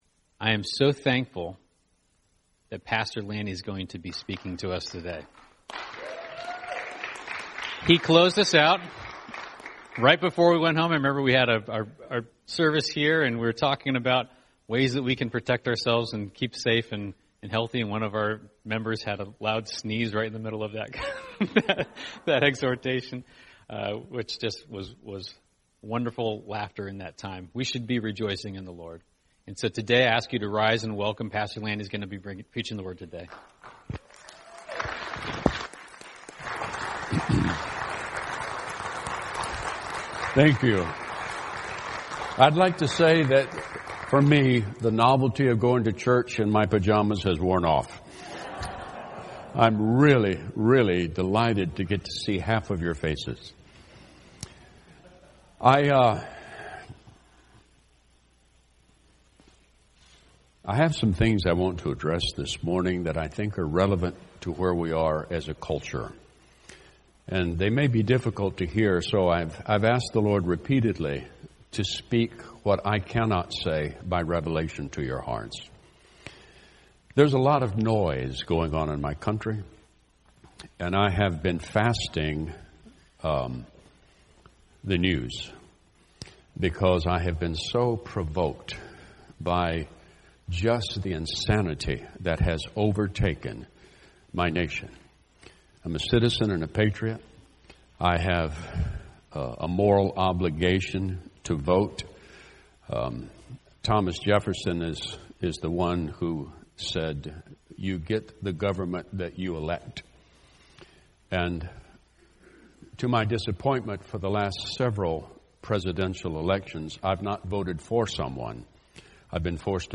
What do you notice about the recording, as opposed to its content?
In our first in-building service since March